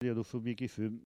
Elle provient de Sallertaine.
Catégorie Locution ( parler, expression, langue,... )